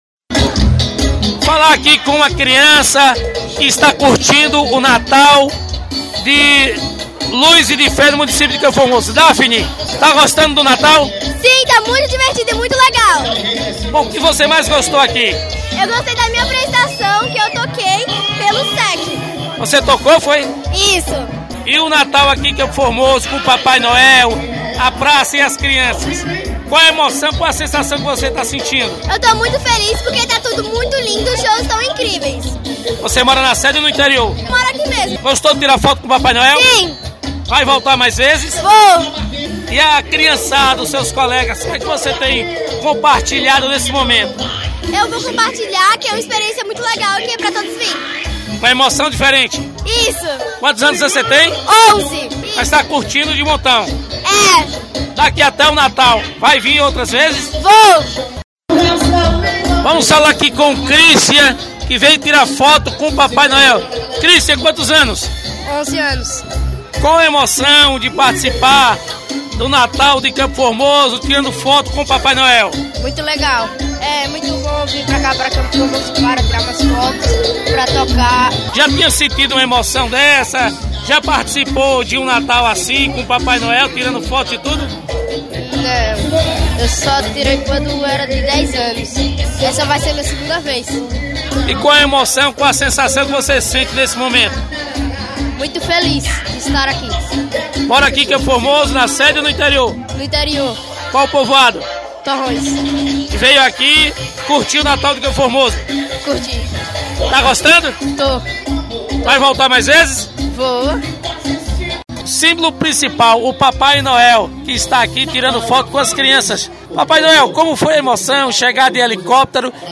Reportagem na chegada do Papai Noel em CFormoso